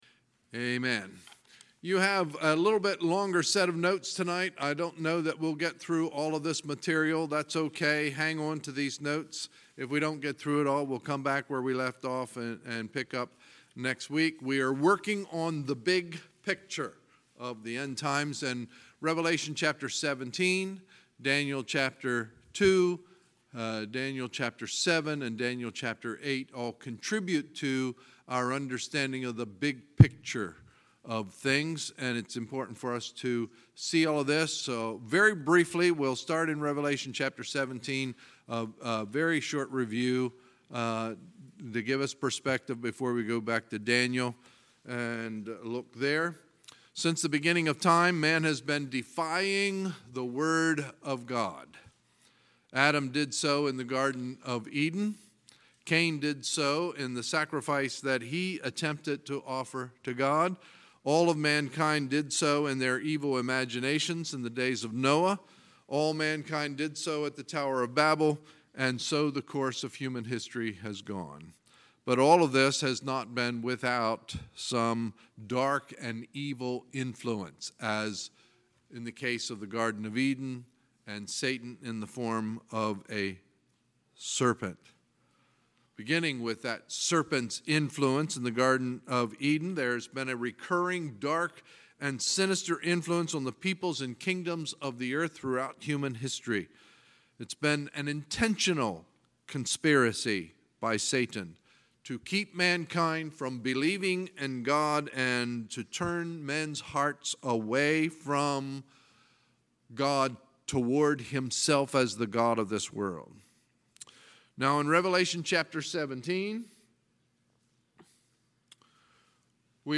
Sunday, July 29, 2018 – Sunday Evening Service
Sermons